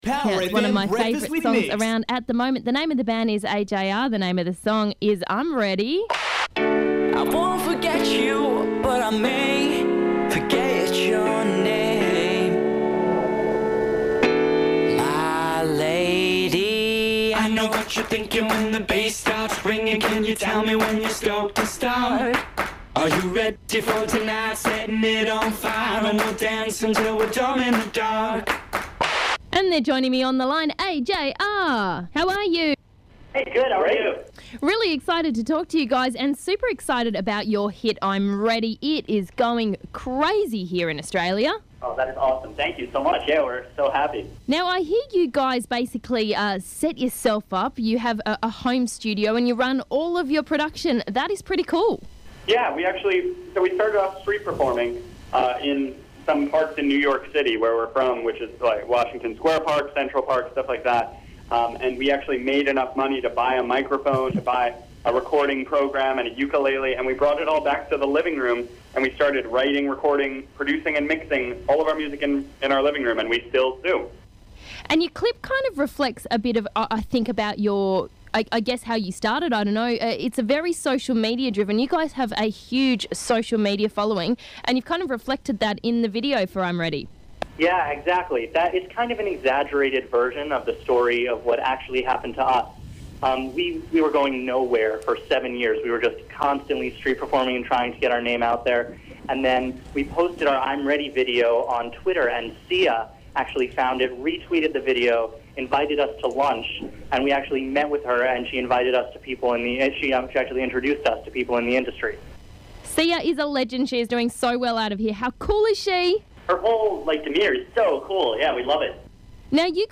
ajr-interview.mp3